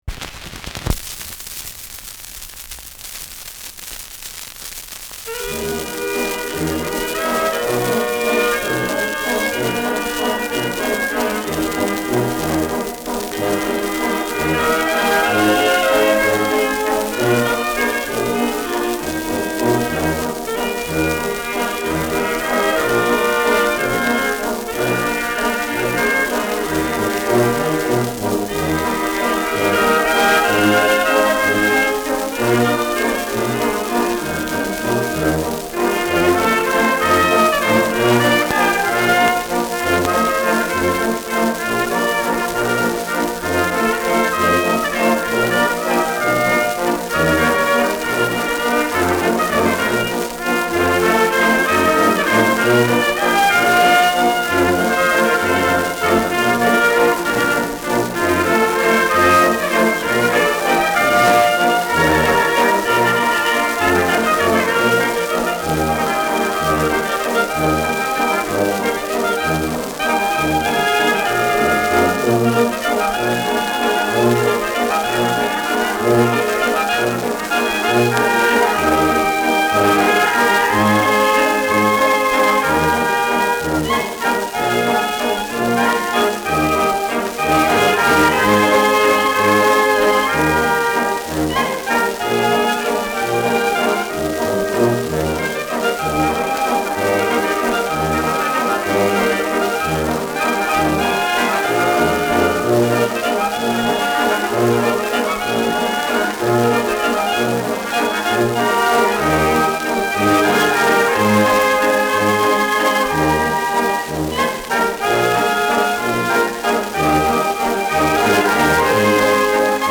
Schellackplatte
Abgespielt : Erhöhtes Grundrauschen
[München] (Aufnahmeort)